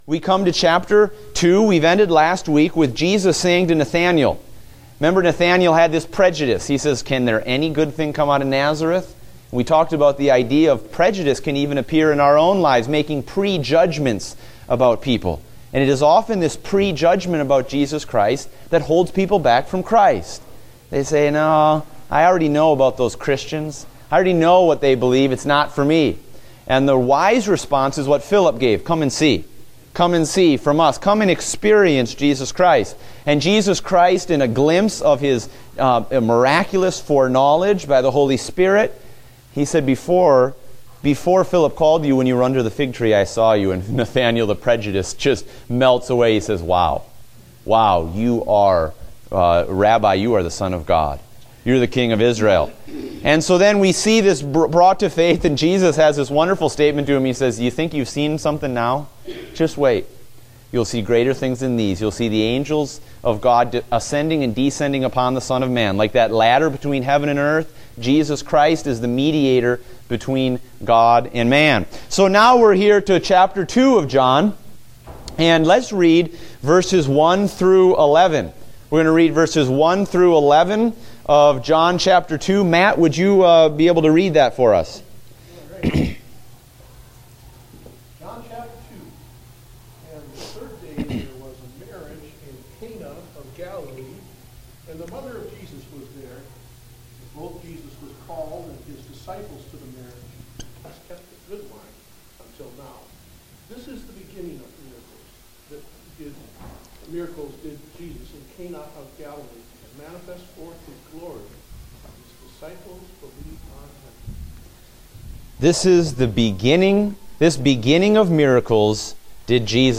Date: May 15, 2016 (Adult Sunday School)